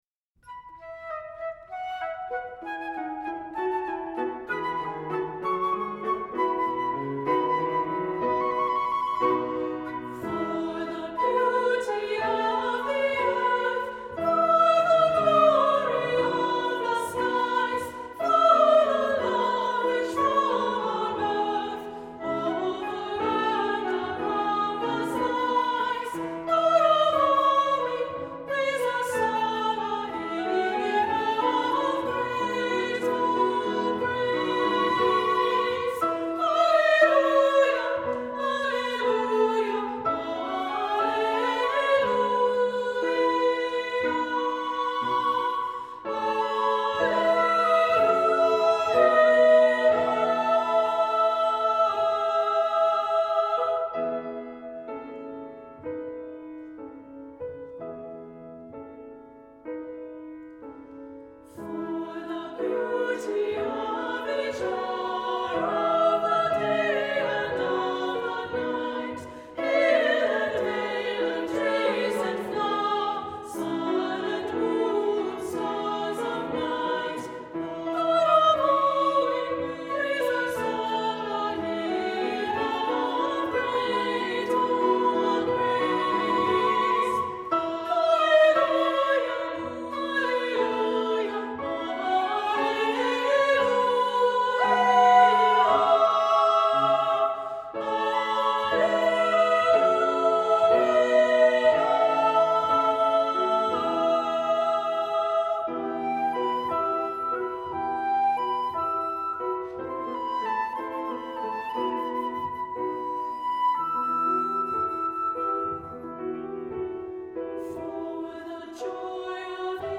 Accompaniment:      With Piano
Music Category:      Christian